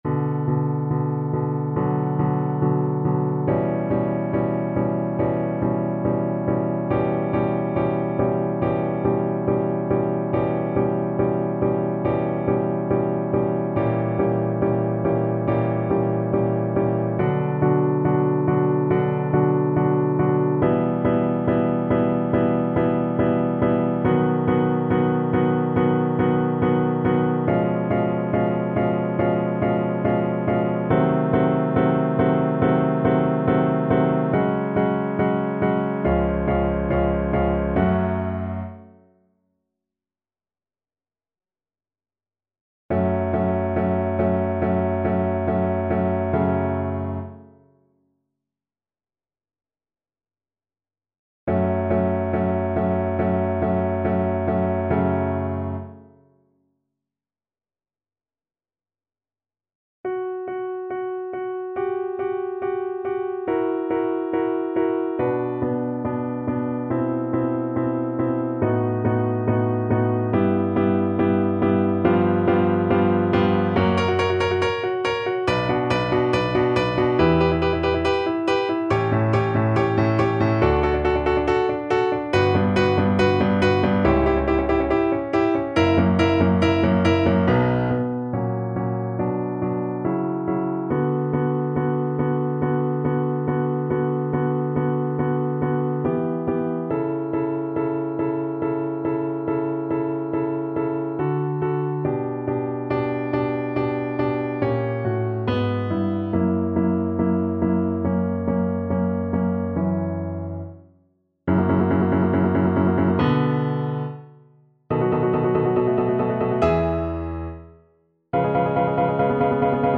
~ = 70 Allegro non molto (View more music marked Allegro)
4/4 (View more 4/4 Music)
Classical (View more Classical Cello Music)